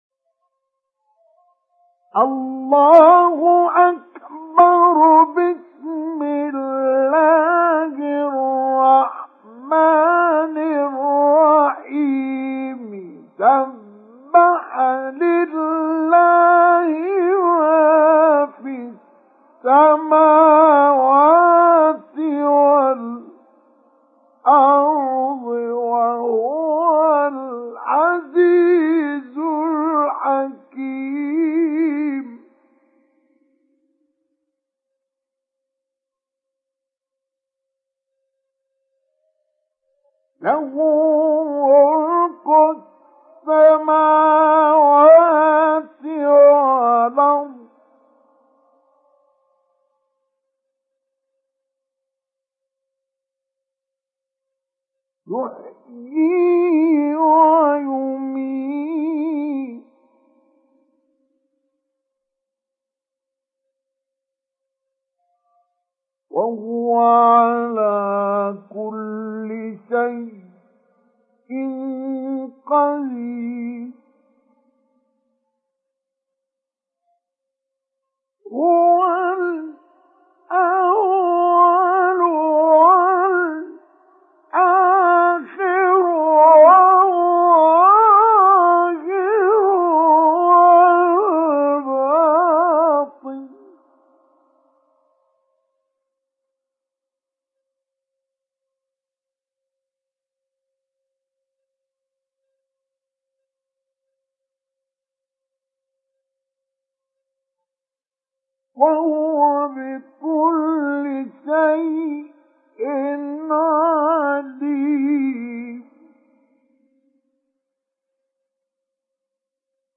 Surat Al Hadid Download mp3 Mustafa Ismail Mujawwad Riwayat Hafs dari Asim, Download Quran dan mendengarkan mp3 tautan langsung penuh
Download Surat Al Hadid Mustafa Ismail Mujawwad